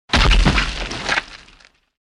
bodyfalldirt08.mp3